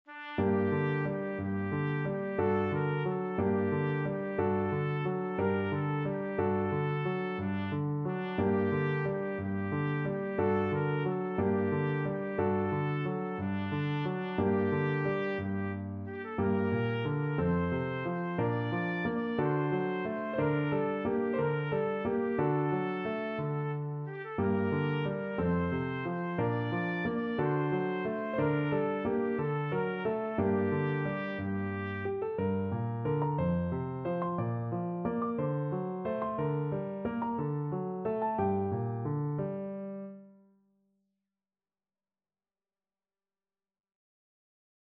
Trumpet
G minor (Sounding Pitch) A minor (Trumpet in Bb) (View more G minor Music for Trumpet )
6/8 (View more 6/8 Music)
~ = 90 Munter
Classical (View more Classical Trumpet Music)